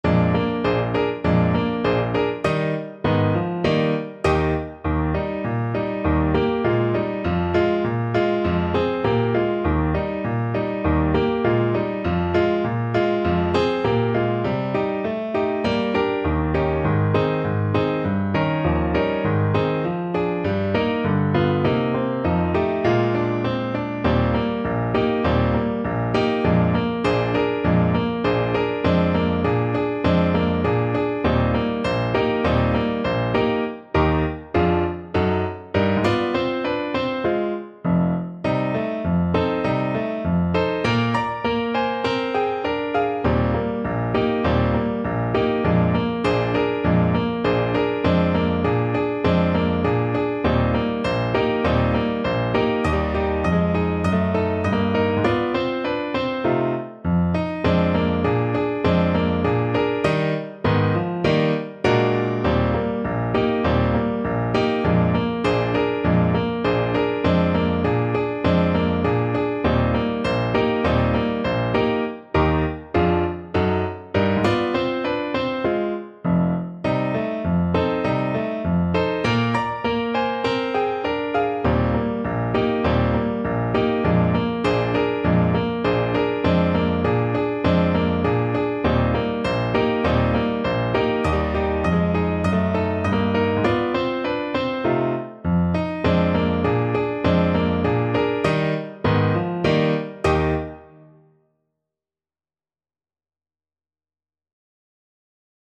Moderato =c.100
Pop (View more Pop Saxophone Music)